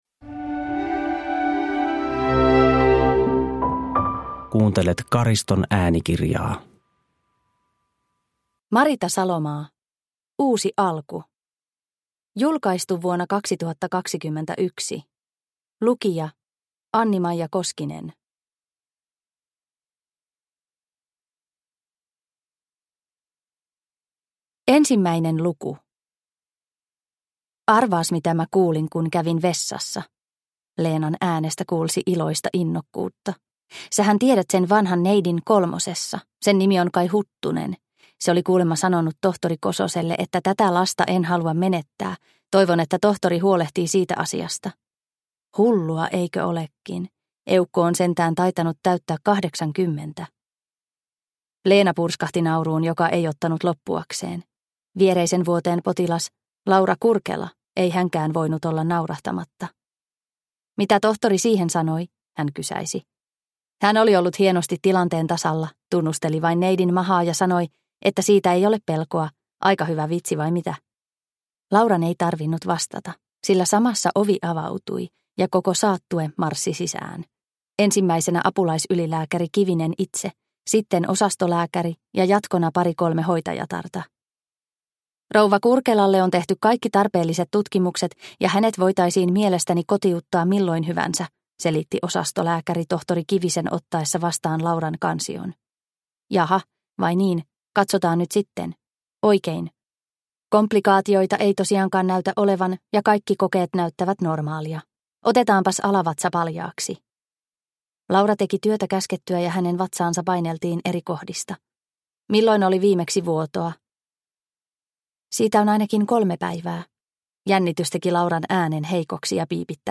Uusi alku – Ljudbok – Laddas ner